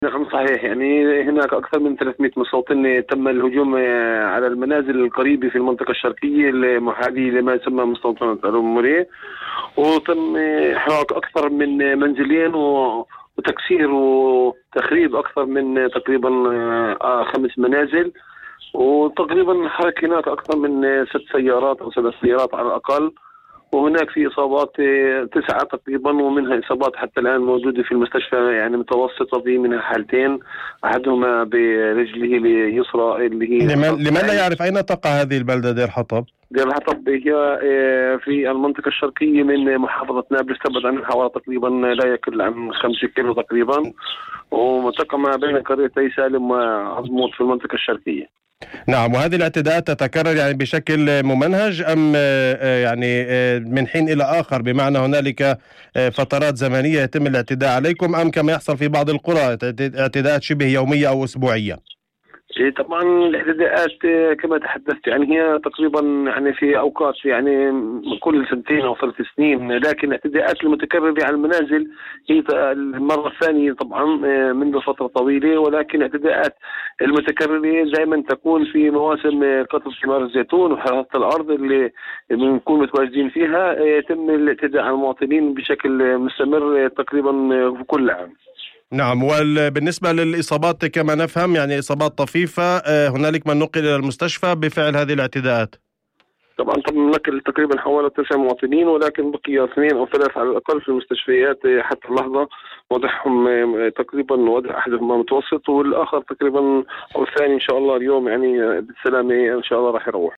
ولمزيد من التفاصيل، تواصلنا في إذاعة الشمس ضمن برنامج "أول خبر"، مع عبد الكريم حسين، رئيس مجلس دير حطب، والذي قال إن الاعتداء جاء بشكل منظم واستهدف المنطقة الشرقية المحاذية لإحدى المستوطنات.